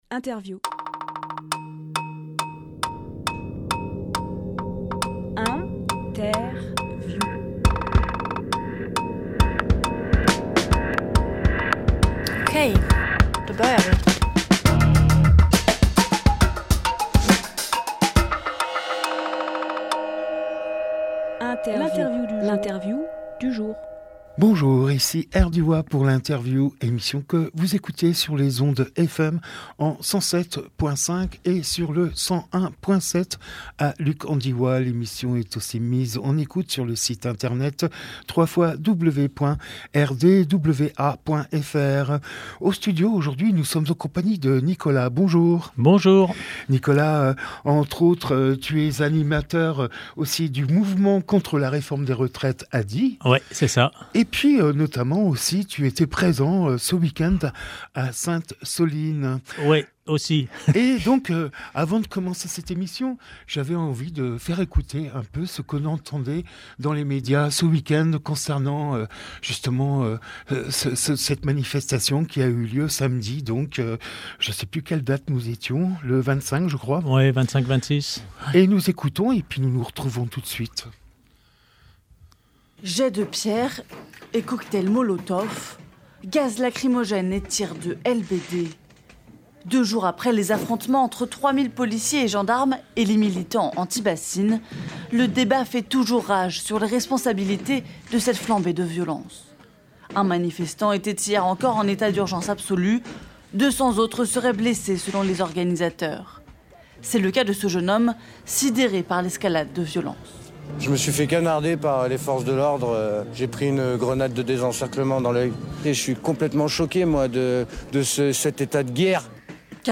Emission - Interview
28.03.23 Lieu : Studio RDWA Durée